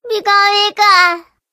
PIKACHU_faint.ogg